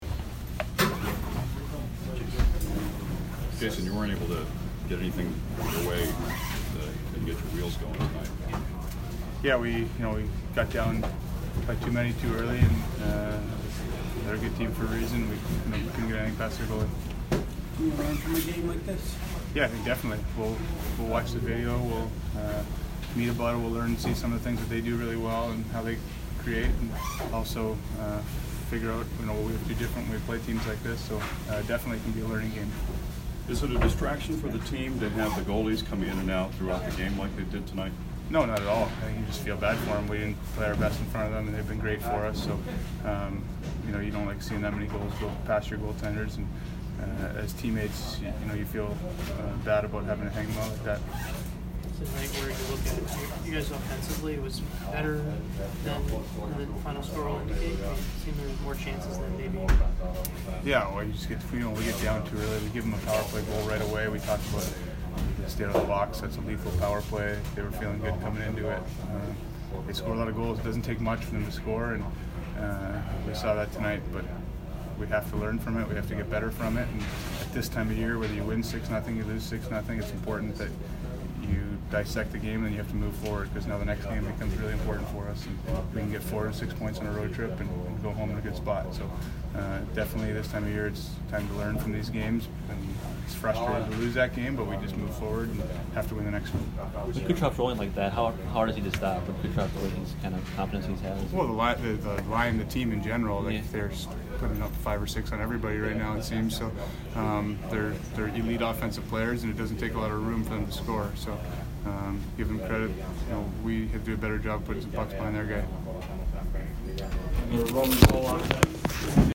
Jason Spezza post-game 2/14